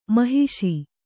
♪ mahiṣi